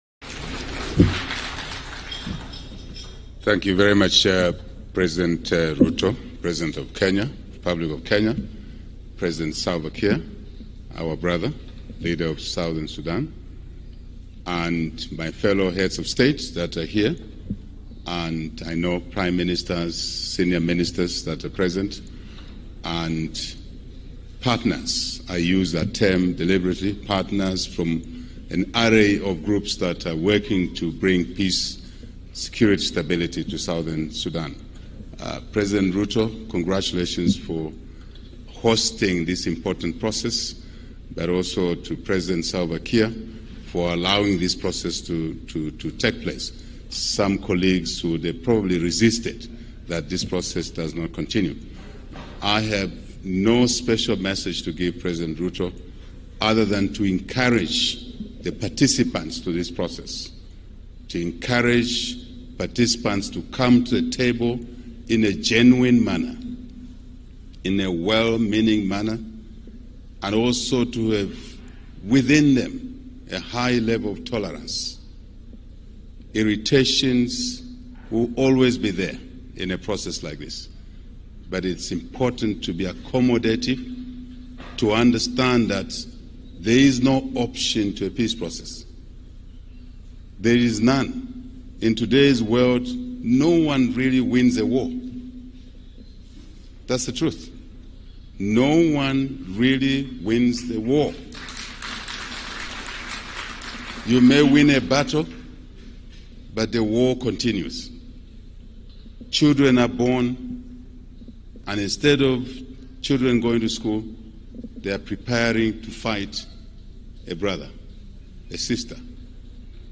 Zambian President Hakainde Hichilema addresses High Level Mediation For South Sudan
Speaking to delegates at the launch of the South Sudan mediation talks in Nairobi, President Hichilema reiterated that wars have no winners.